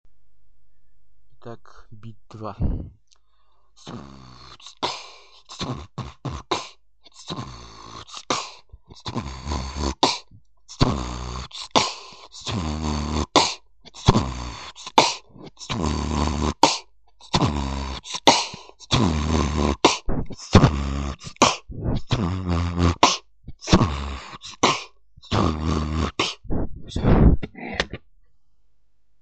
dubstep биты
конечно можно и без горлового баса если вы не умеете, заменим его на волнообразные В.Б. :
tt-bww-tt-kch-tt-bw w w-kch